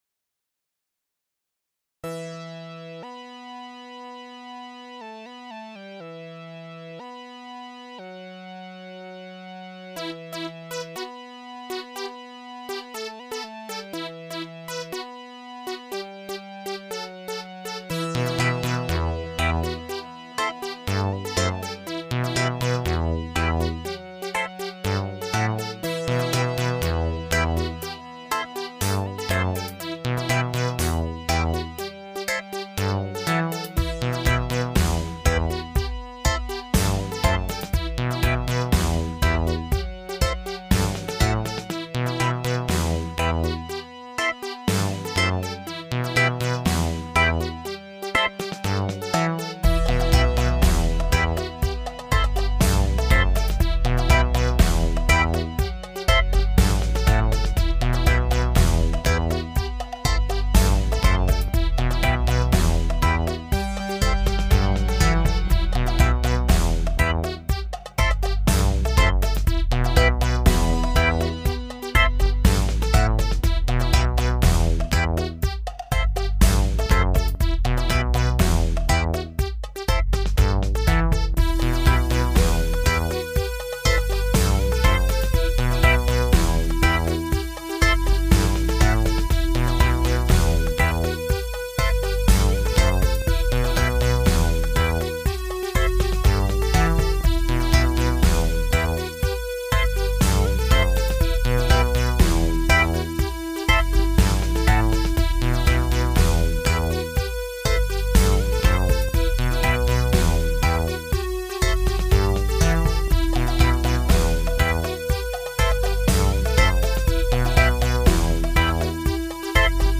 Big Warriors DUB!!!
hammer-dub-part-1-extrait-3Kscr